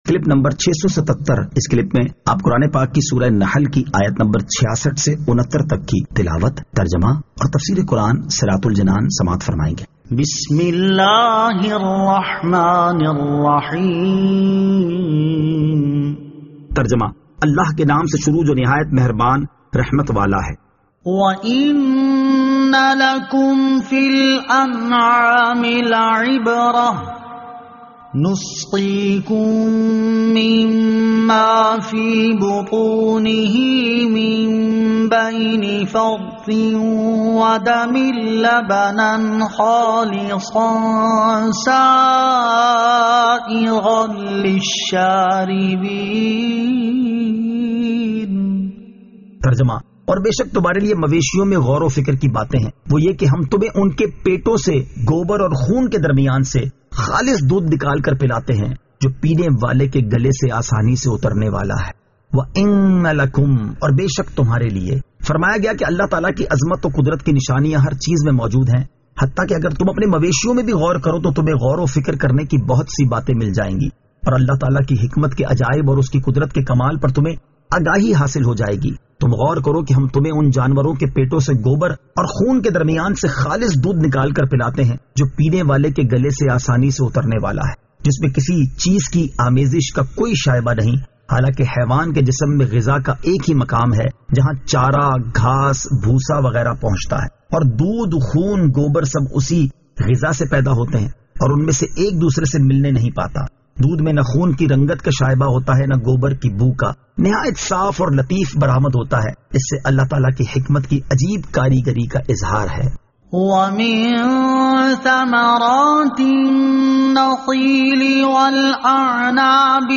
Surah An-Nahl Ayat 66 To 69 Tilawat , Tarjama , Tafseer